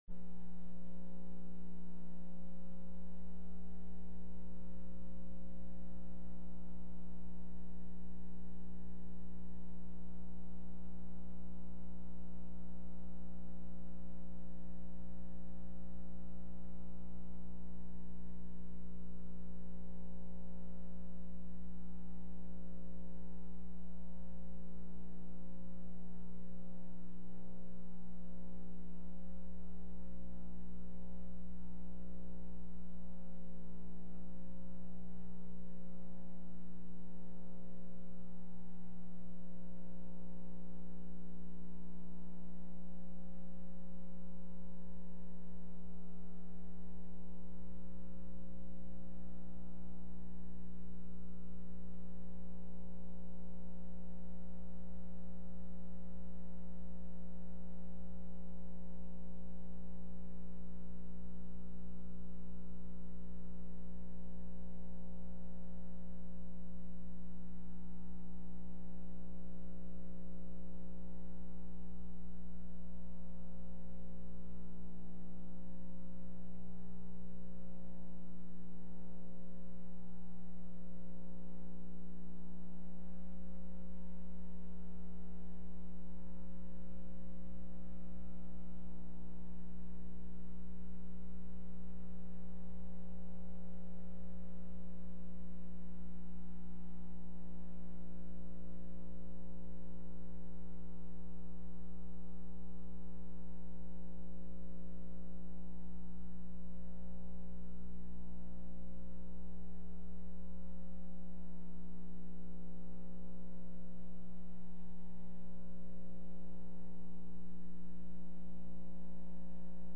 03/22/2005 01:30 PM House FINANCE